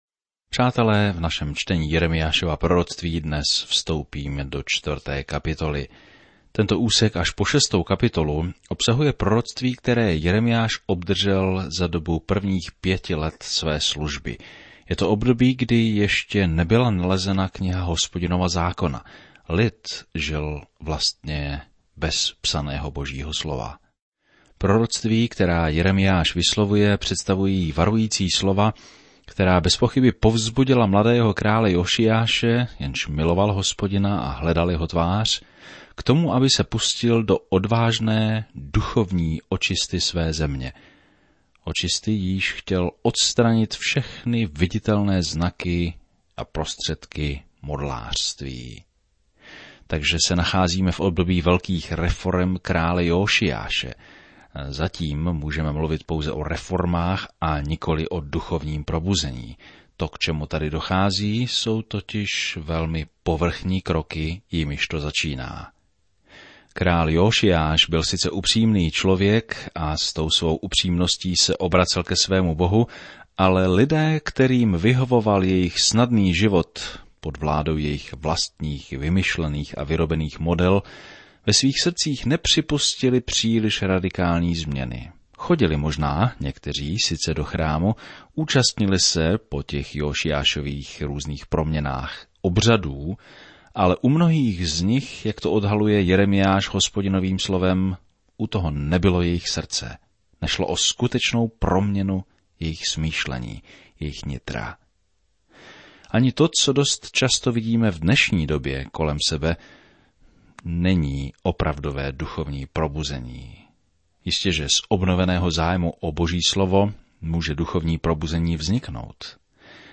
Je zamýšlen jako každodenní 30ti minutový rozhlasový pořad, který systematicky provádí posluchače celou Biblí.